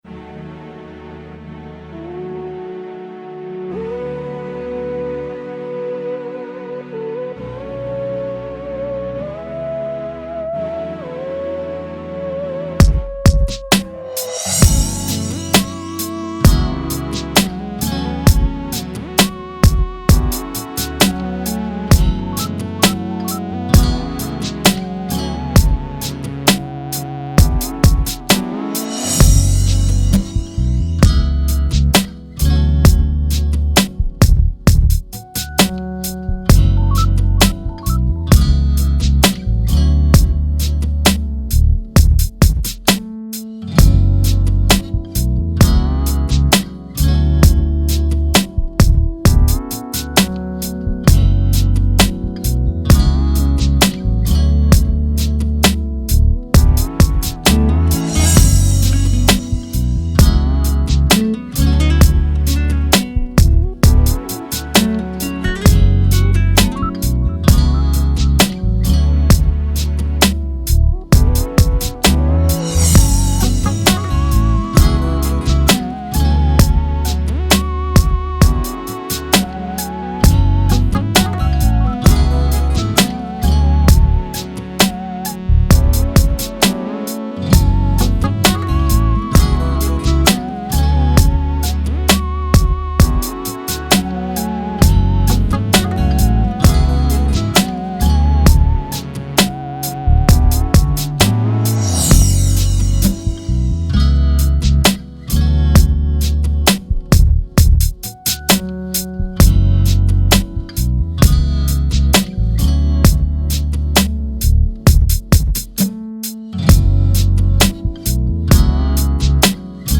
R&B Throwback Instrumentals